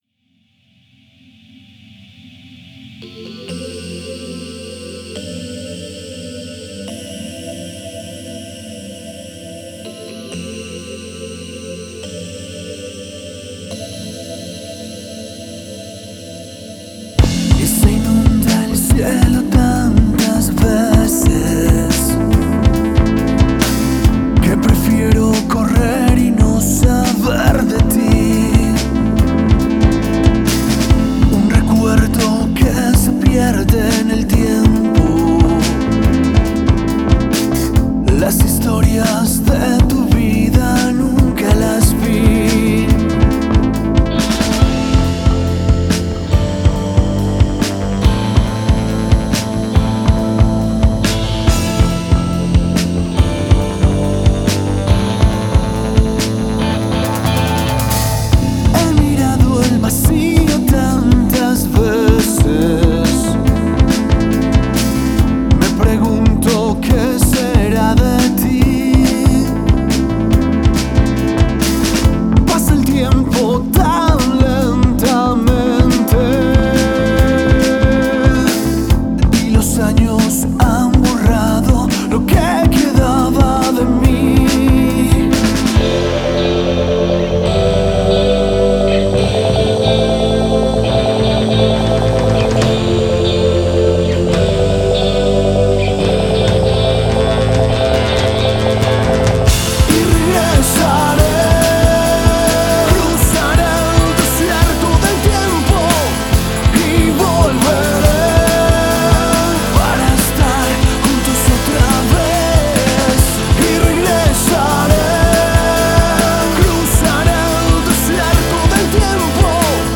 banda de rock alternativo
Rock Alternativo